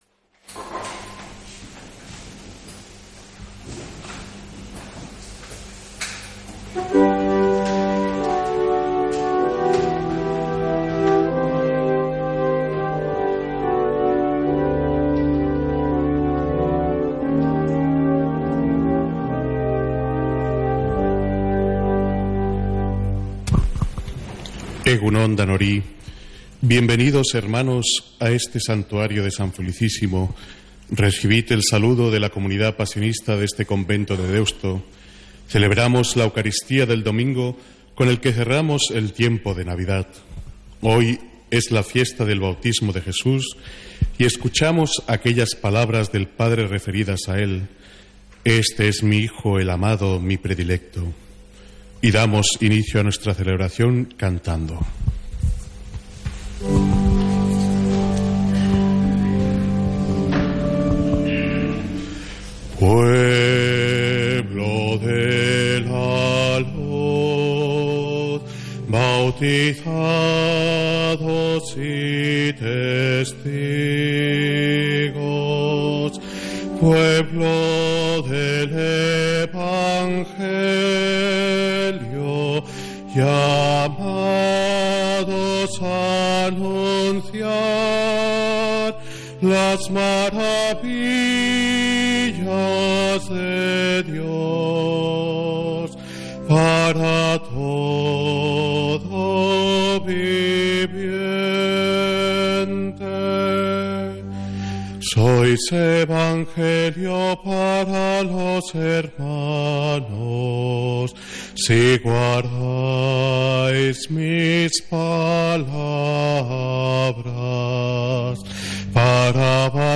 Santa Misa desde San Felicísimo en Deusto, domingo 12 de enero